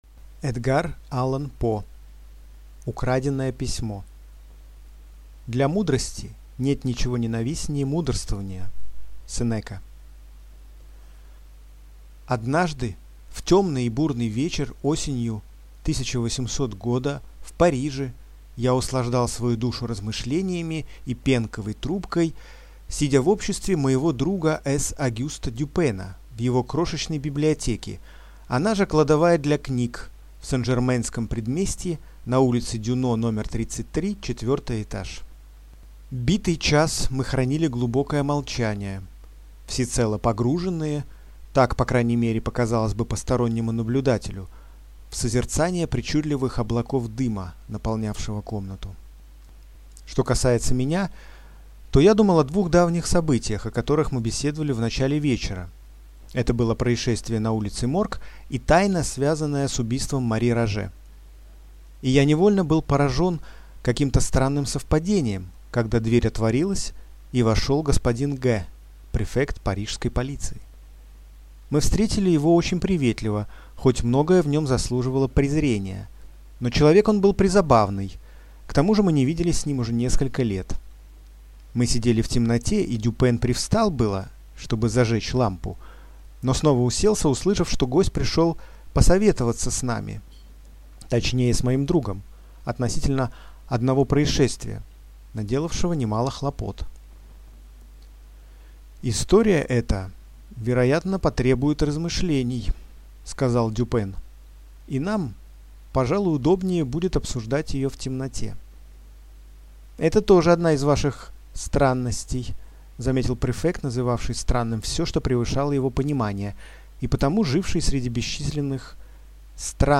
Аудиокнига Украденное письмо | Библиотека аудиокниг